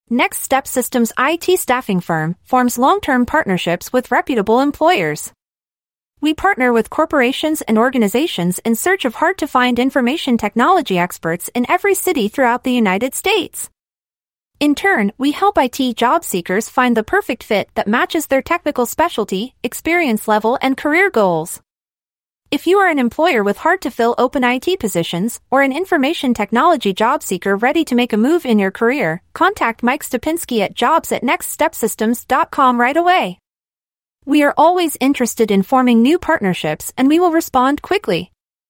Please take a moment to listen to an audio file about our IT staffing firm and information technology recruiting agency generated by Artificial Intelligence (AI). Next Step Systems partners with corporations and organizations in search of hard-to-find information technology experts in every city throughout the United States.